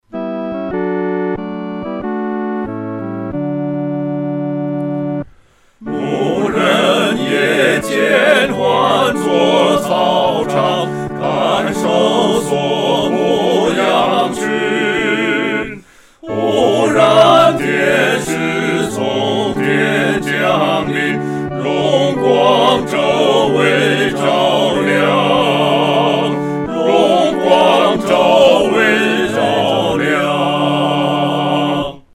合唱（四声部）
牧人闻信-合唱（四声部）.mp3